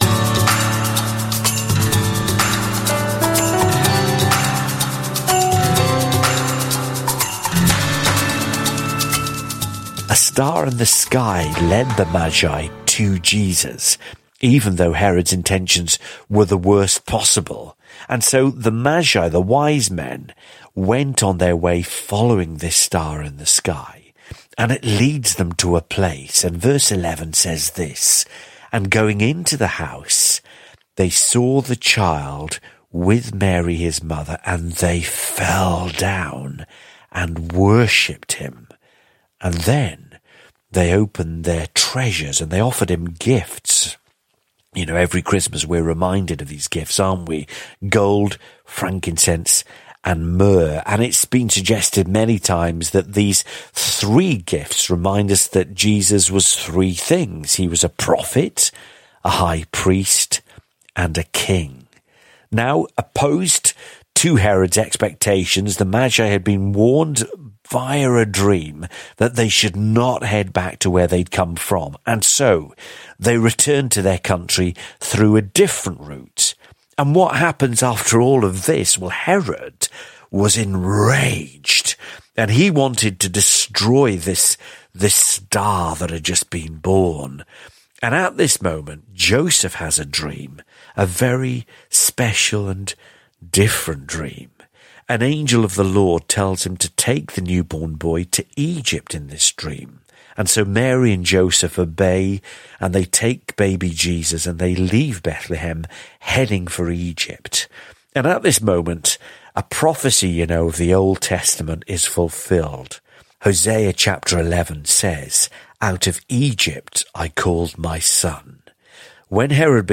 This episode explores the story of the Magi who visited Jesus after his birth, highlighting their journey and the significance of their gifts. Listen to this excerpt of the Mission 66 lesson on Matthew, chapter 2.